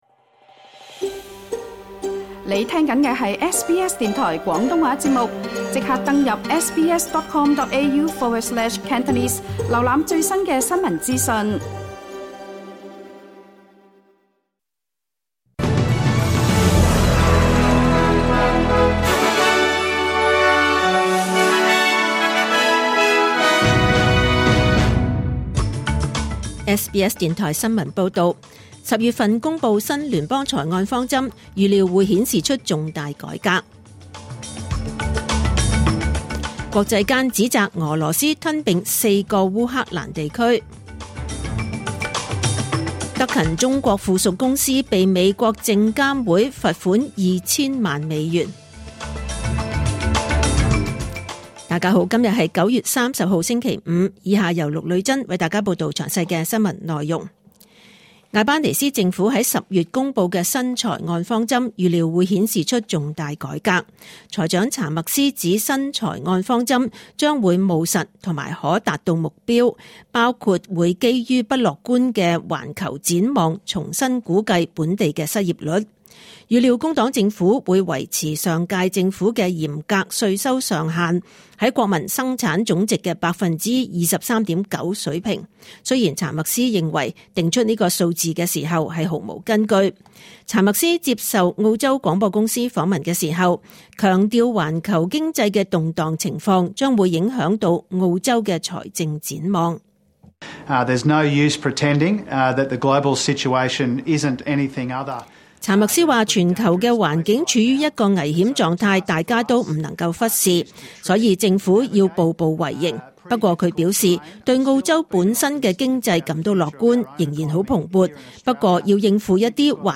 SBS 廣東話節目中文新聞 Source: SBS / SBS Cantonese